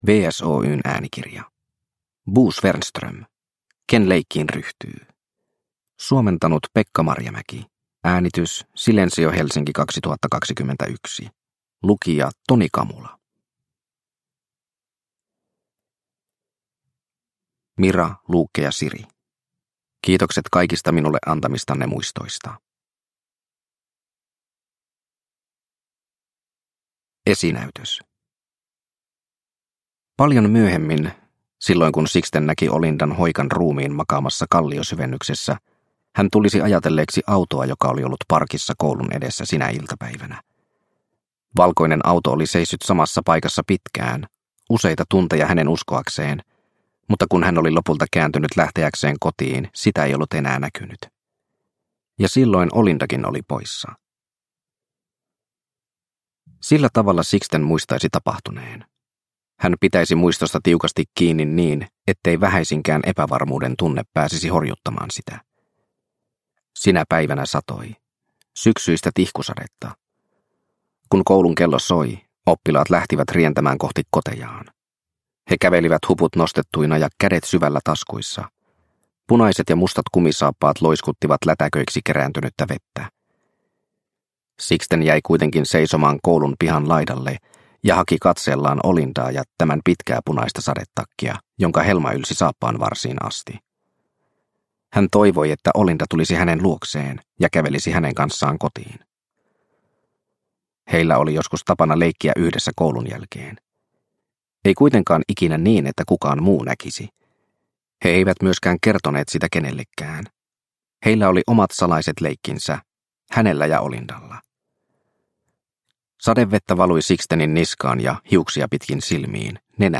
Ken leikkiin ryhtyy – Ljudbok – Laddas ner